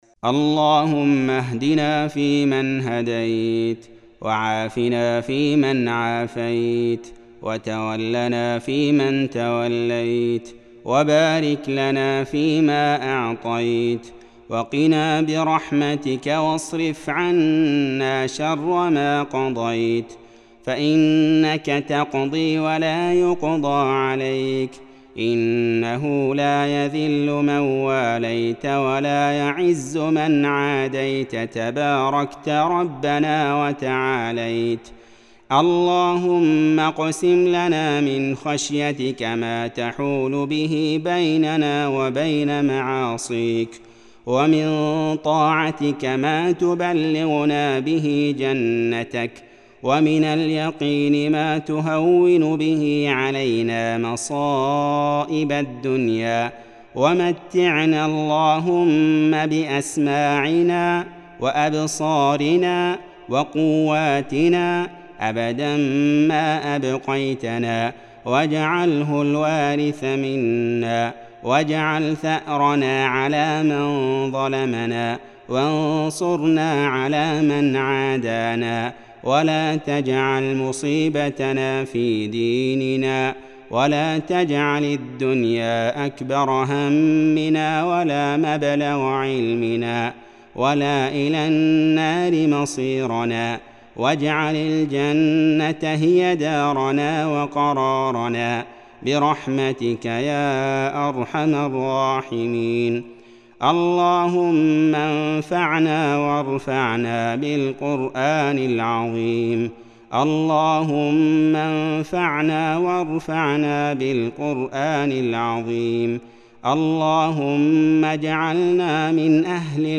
أدعية وأذكار
دعاء خاشع ومؤثر بصوت القارئ